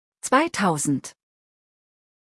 101 hunderteins хундэрт-айнс